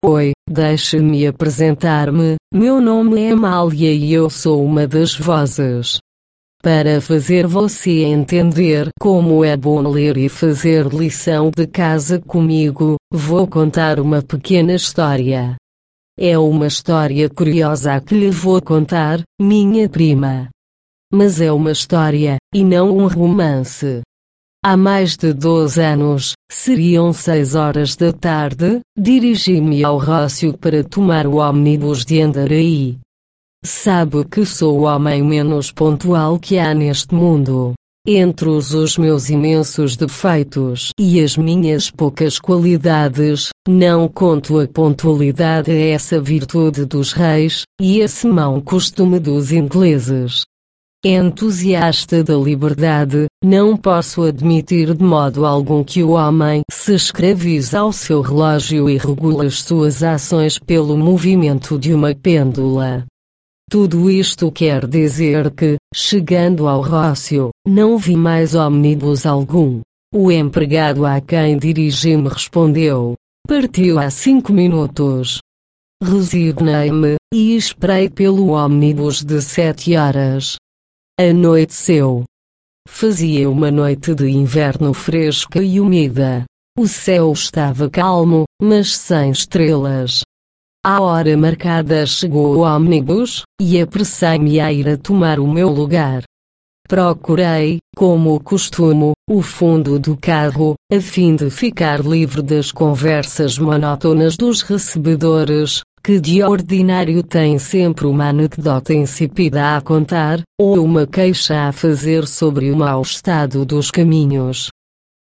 Per questo motivo, la voce risulta estremamente gradevole, chiara e fluente e priva del timbro metallico tipico delle voci artificiali.
Voce portoghese Amalia
Lenta
Amalia-lenta.mp3